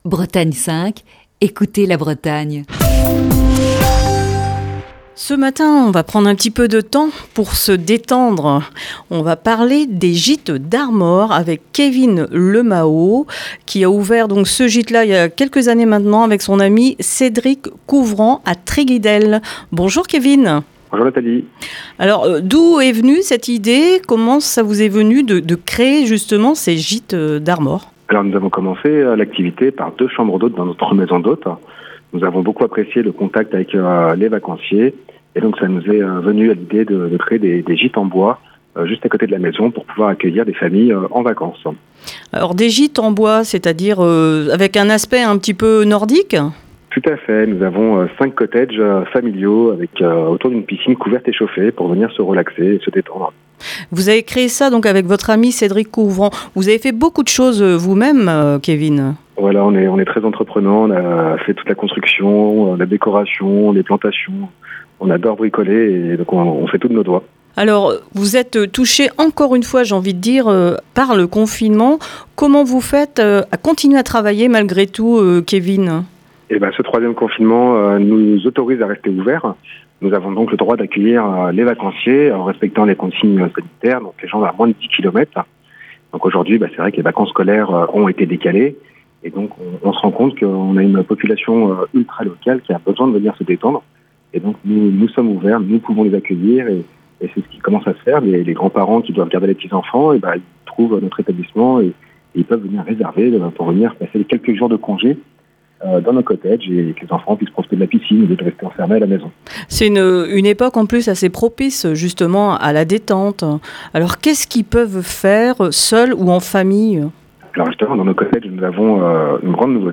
Dans le coup de fil du matin de ce jeudi